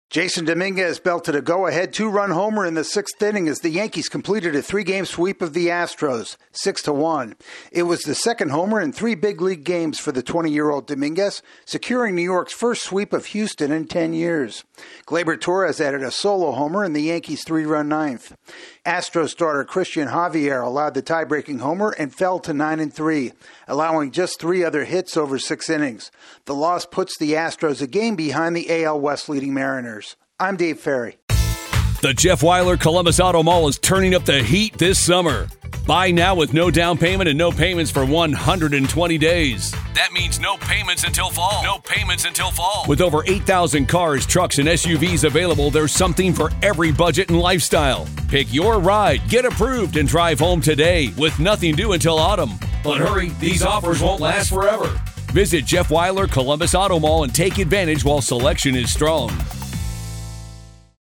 The Astros waste a chance to grab a share of the AL West lead. AP correspondent